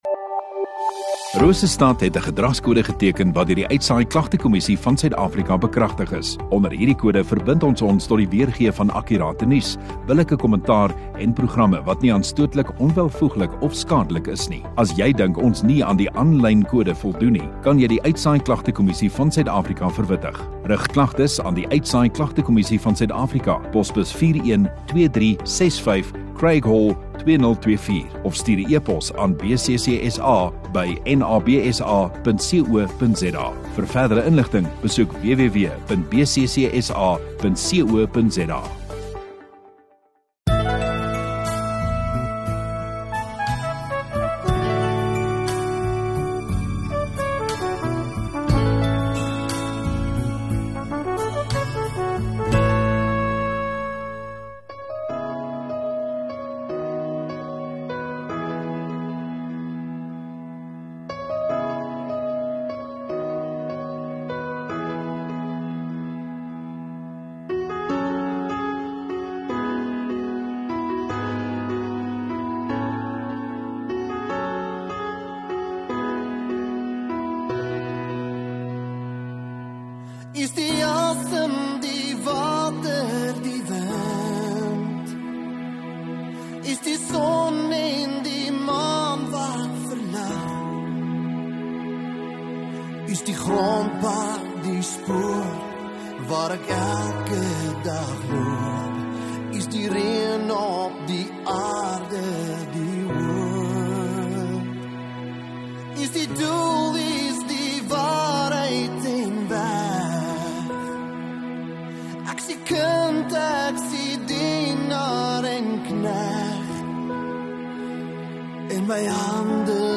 7 Jun Vrydag Oggenddiens